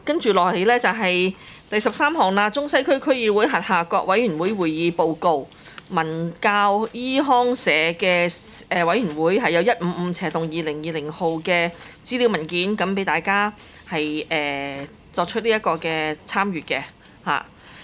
區議會大會的錄音記錄
中西區區議會第六次會議